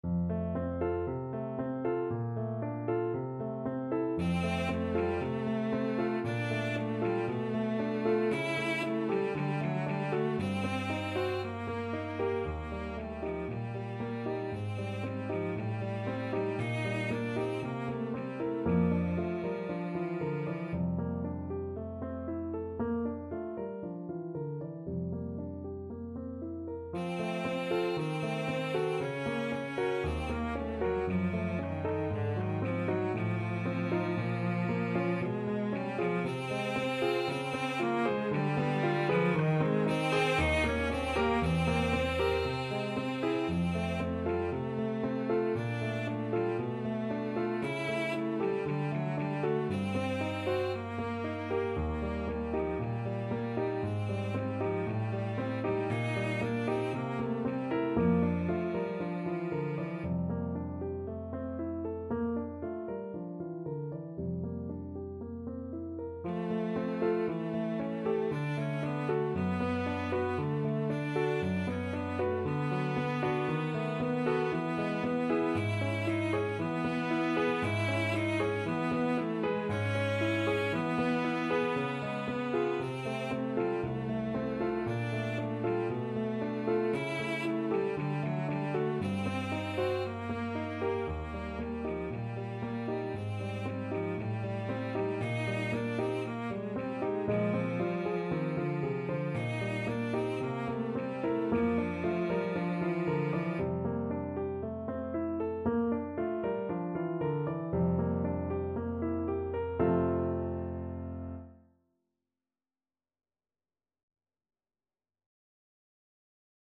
Chanson d'amour Cello version
F major (Sounding Pitch) (View more F major Music for Cello )
4/4 (View more 4/4 Music)
Allegro moderato =116 (View more music marked Allegro)
Cello  (View more Intermediate Cello Music)
Classical (View more Classical Cello Music)